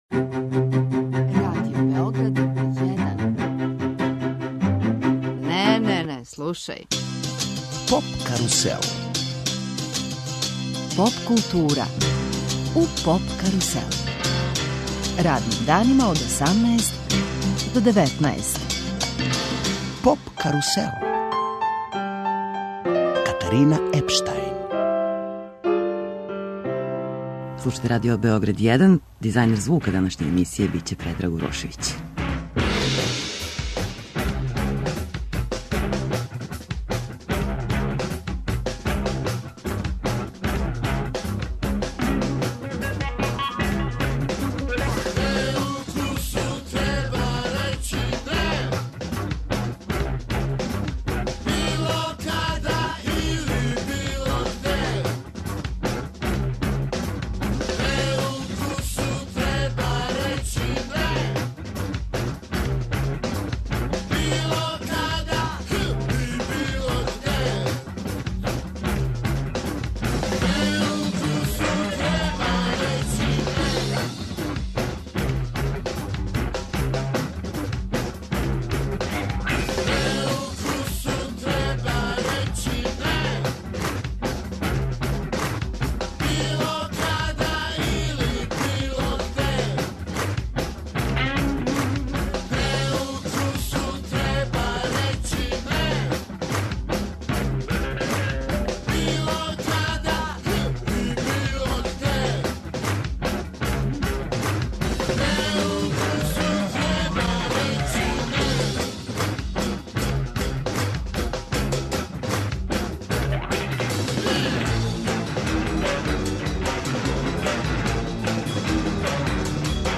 Емисија је посвећена саставу Дисциплина кичме. Наш гост је Душан Којић - Која, басиста, композитор и певач.